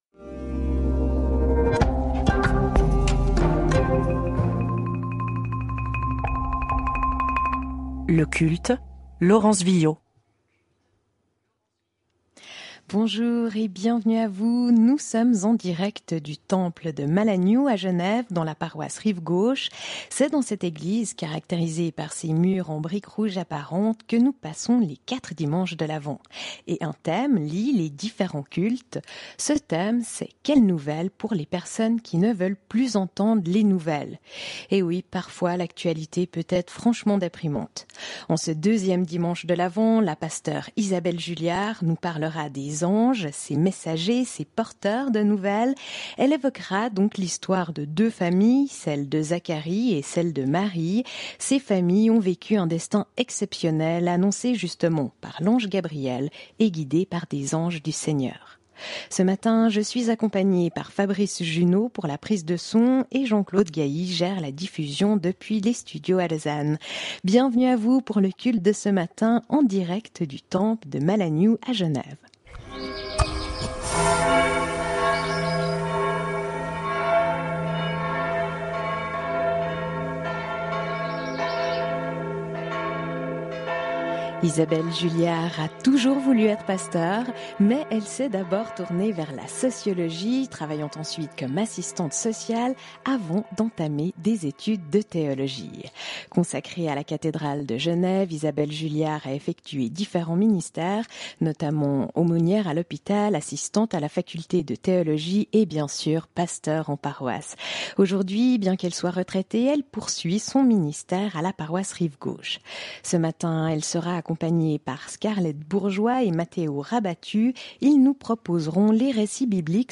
Prédication